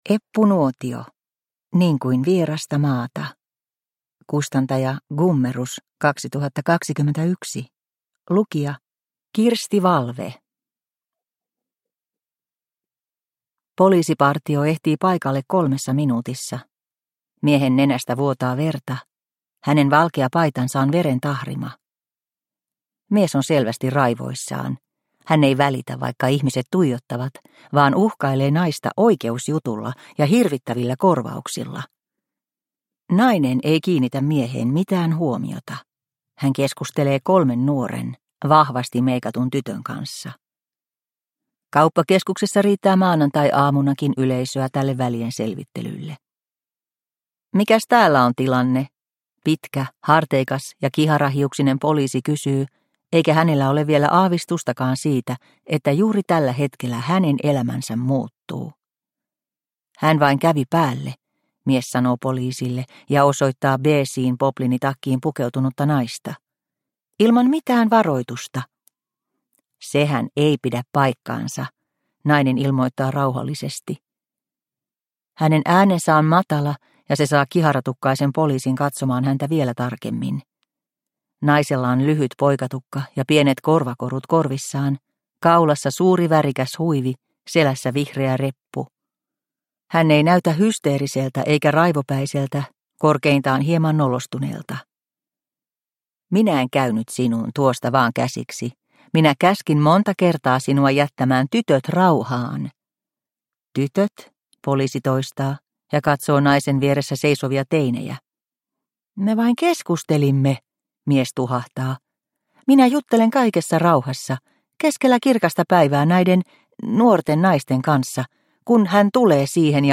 Niin kuin vierasta maata – Ljudbok – Laddas ner